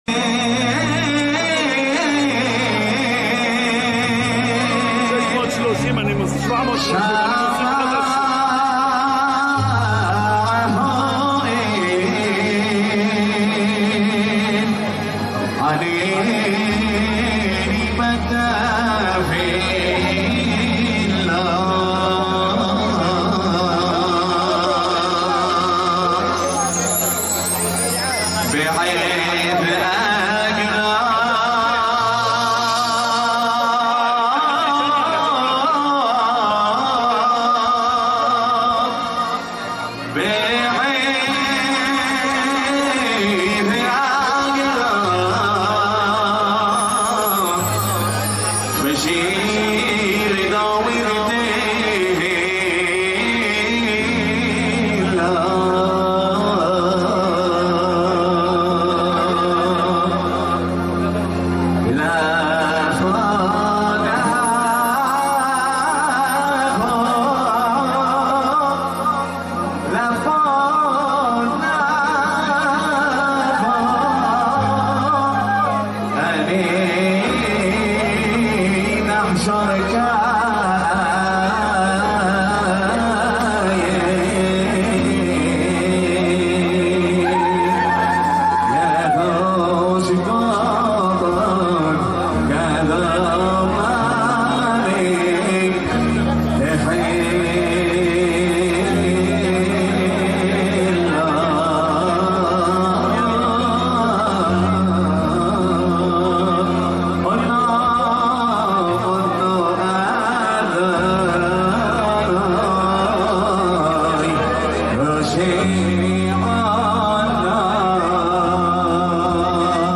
ריקוד תימני
הקפות שניות מרכזיות בירושלם תובבא. שירה וריקודים כמסורת יהודי קק תימן